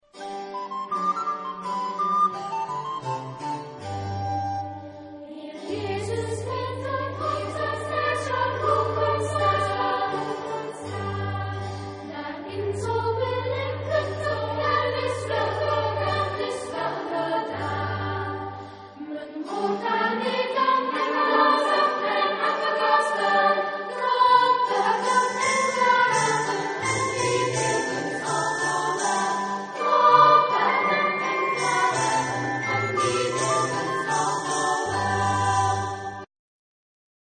Género/Estilo/Forma: Profano ; Sagrado ; Canción de Navidad
Carácter de la pieza : brillante
Tonalidad : sol mayor